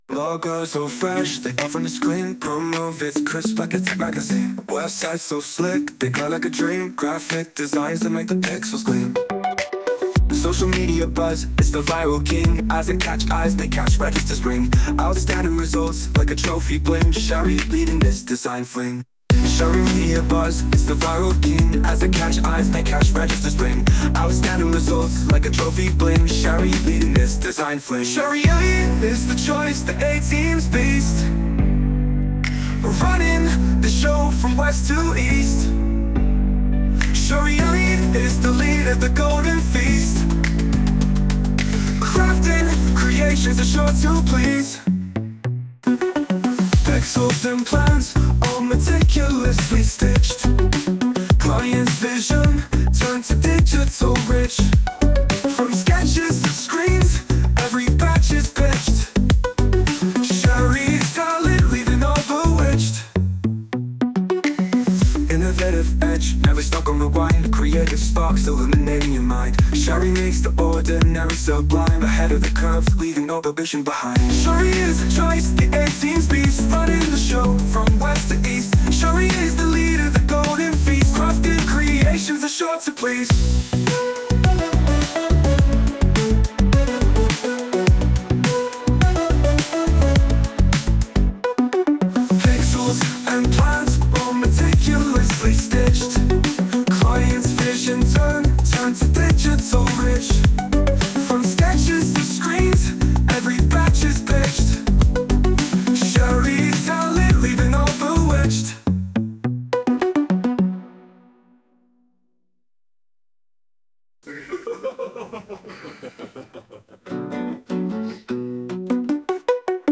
Music Genre: Pop (Male Singer)